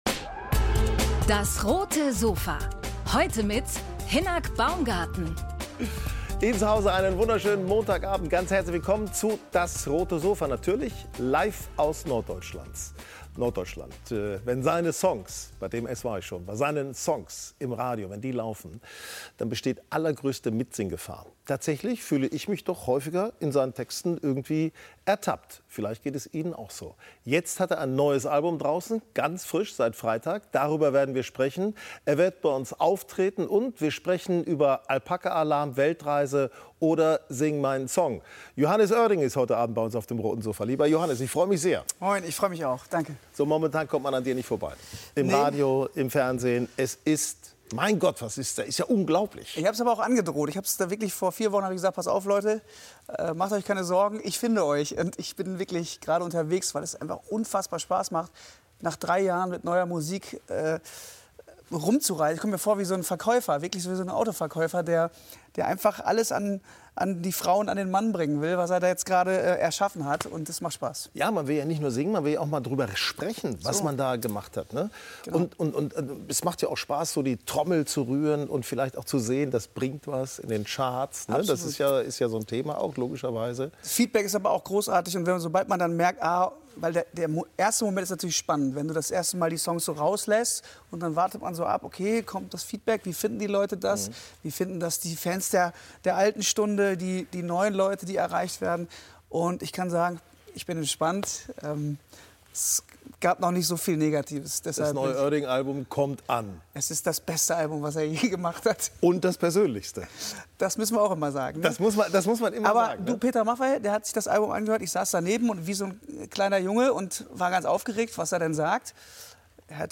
Musiker Johannes Oerding im Talk mit Hinnerk Baumgarten ~ DAS! - täglich ein Interview Podcast